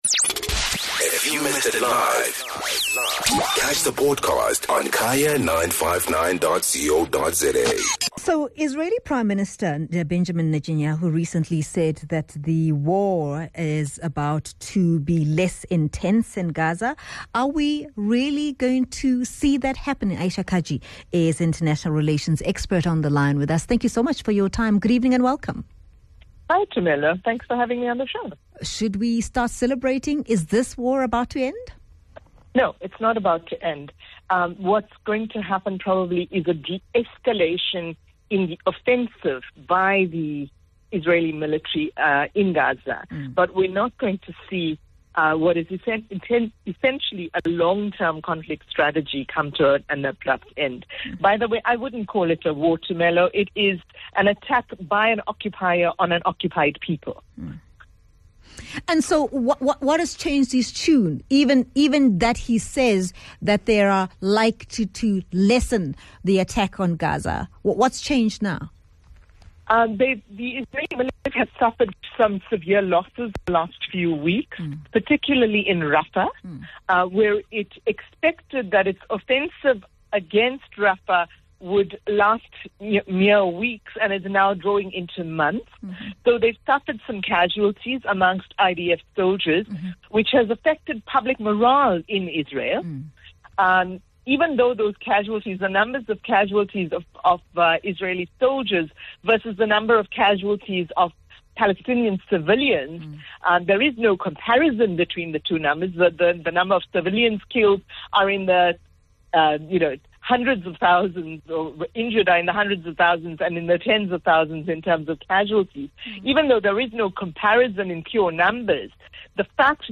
International Relations Expert